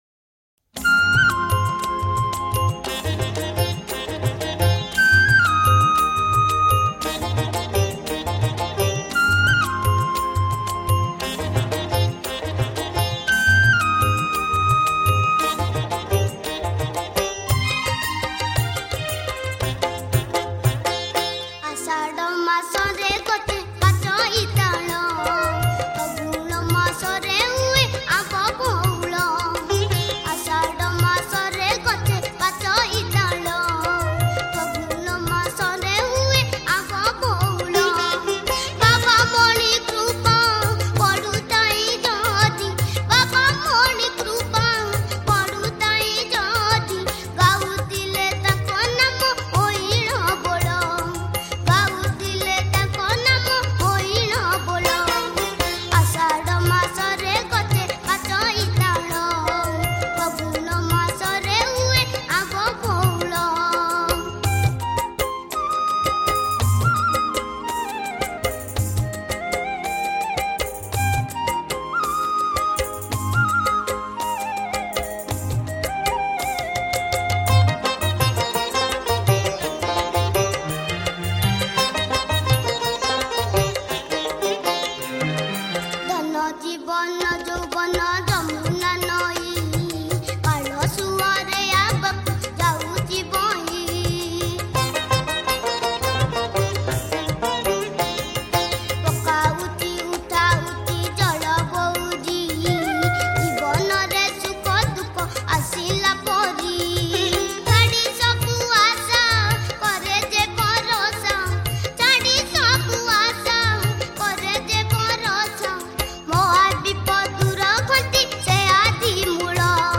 Siba Bhajan